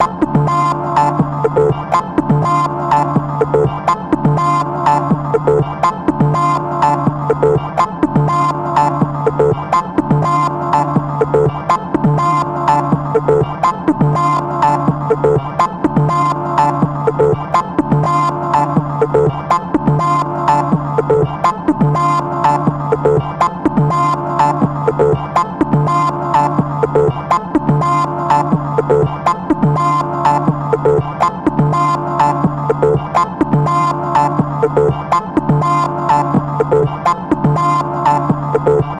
スマートフォンがビートを刻むサウンド。